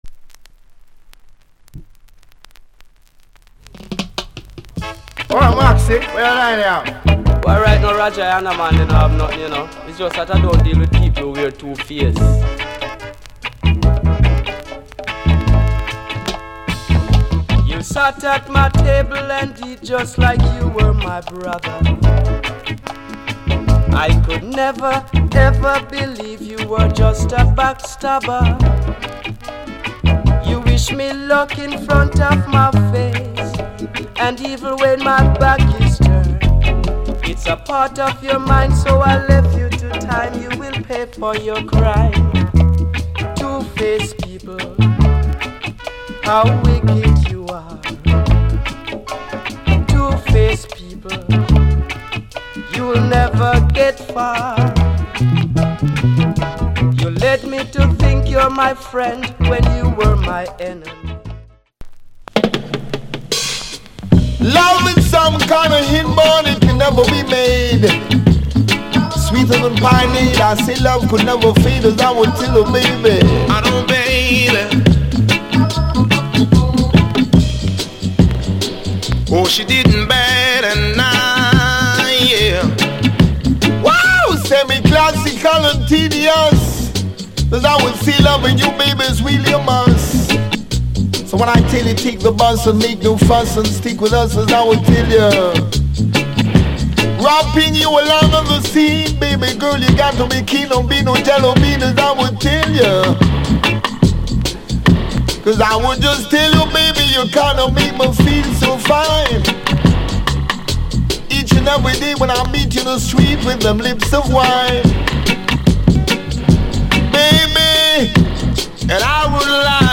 Genre Reggae70sMid / [A] Male Vocal [B] Male DJ